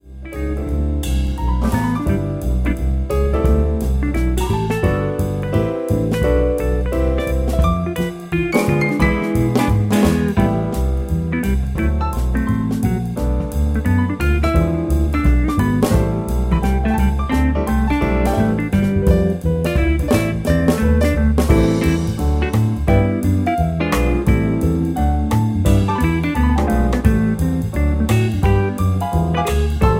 Backing track Karaoke
Pop, Jazz/Big Band, 1990s